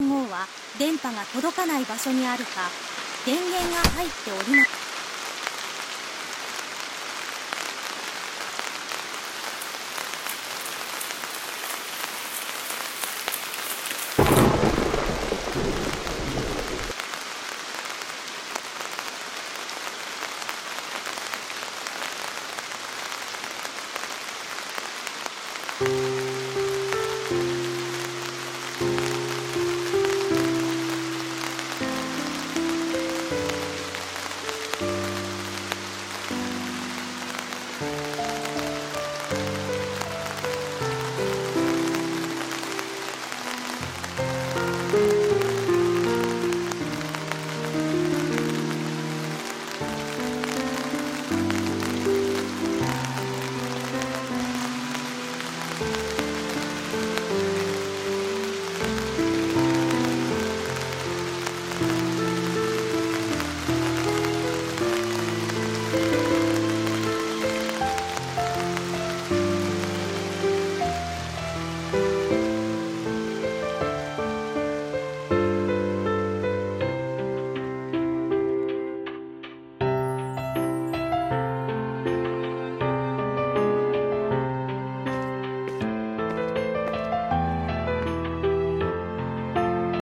【二人声劇】雨に咲く片花